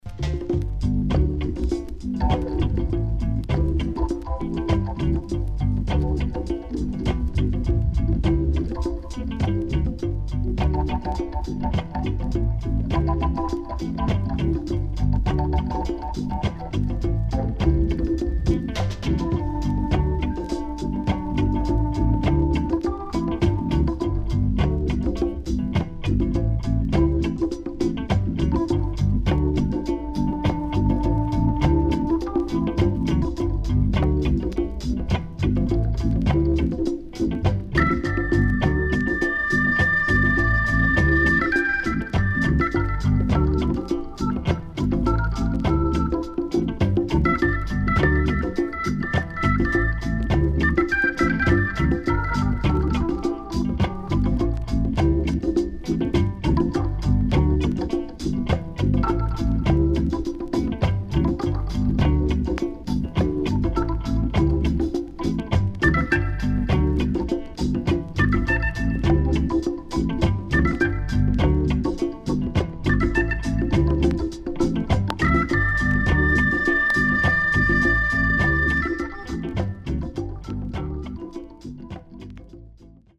SIDE A:少しチリノイズ、プチノイズ入ります。